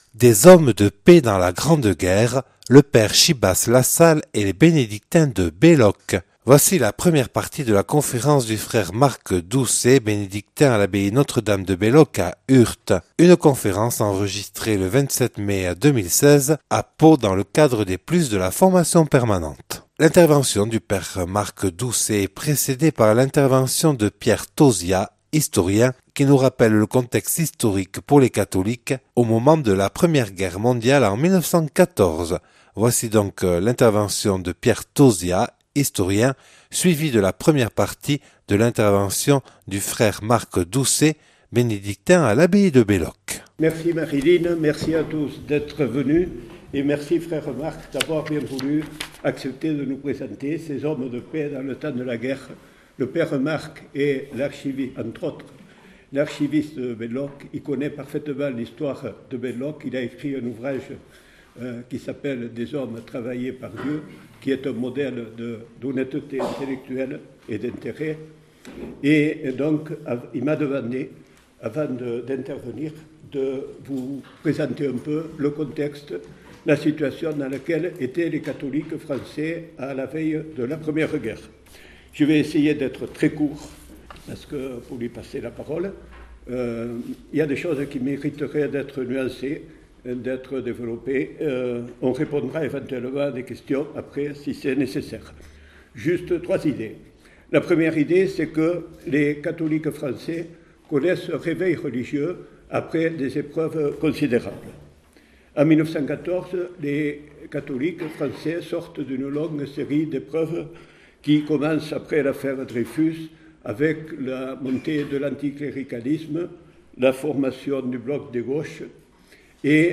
(Enregistrée à Pau le 27 mai 2016).